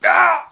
Player_Hurt 03.wav